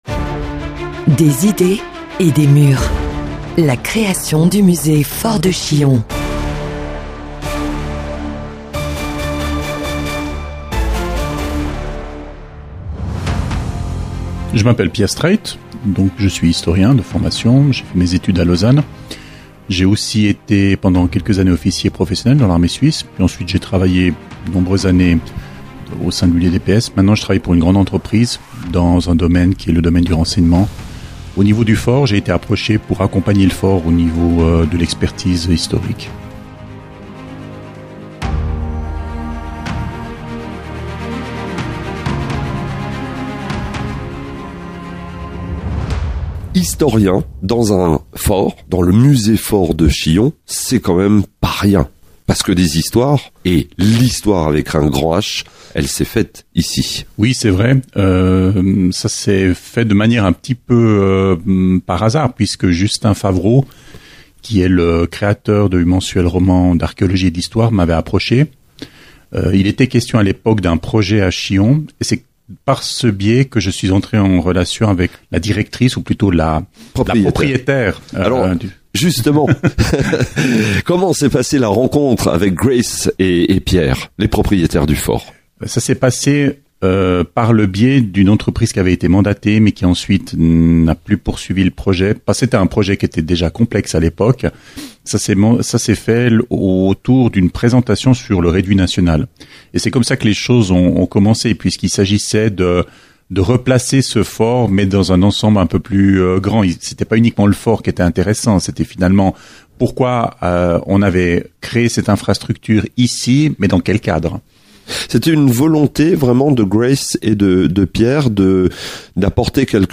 Une immersion auditive avec des experts
Ces conversations inédites vous plongeront dans les coulisses de l’aventure du Fort, entre histoire, défis et regards passionnés.